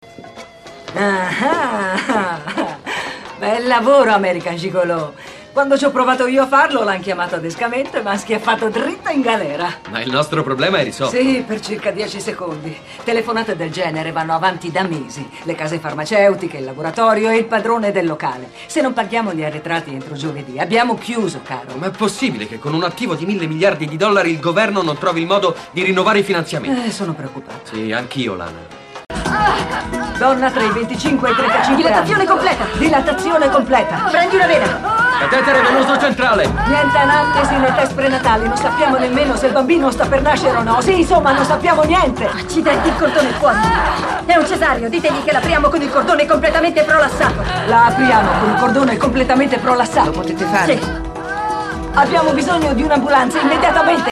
nel telefilm "Squadra Med - Il coraggio delle donne", in cui doppia Jenifer Lewis.